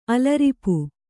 ♪ alaripu